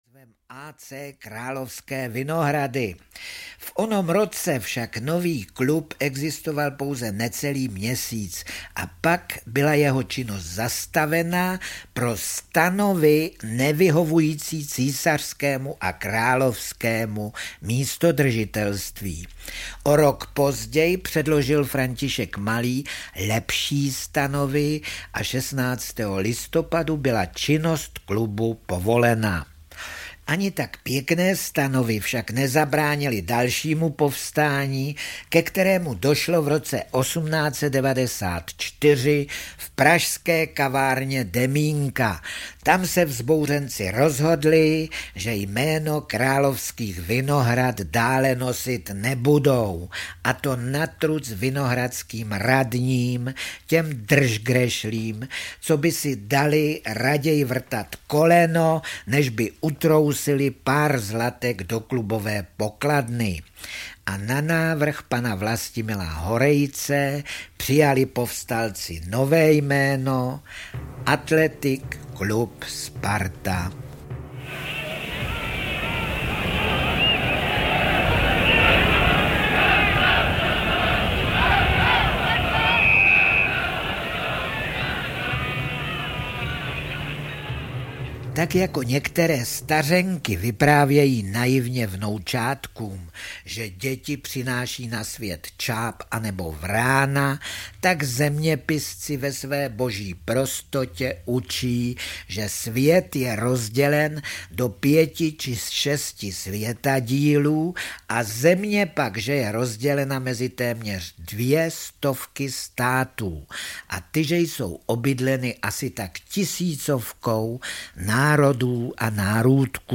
Má stoletá láska Sparta audiokniha
Ukázka z knihy